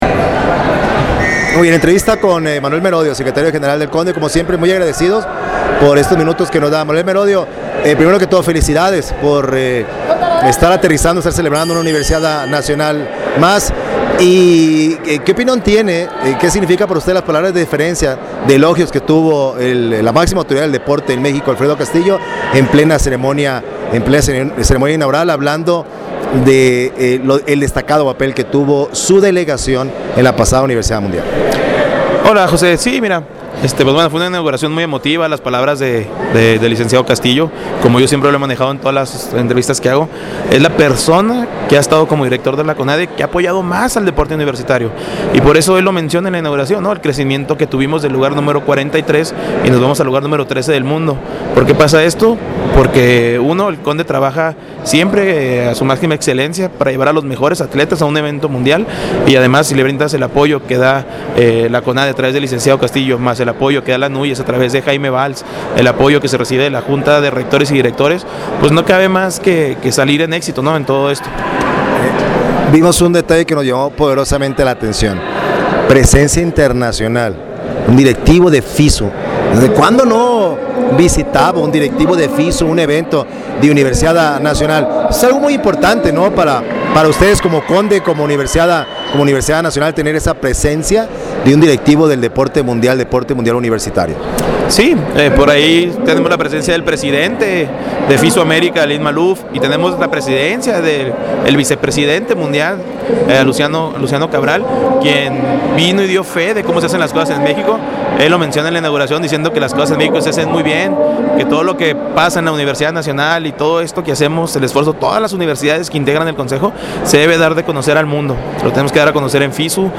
Al entrevistarlo en la capital mexiquense donde sigue de cerca los eventos de la edición 22 de la Universiada Nacional con sede en la Universidad Autónoma del Estado de México.